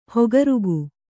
♪ hogarugu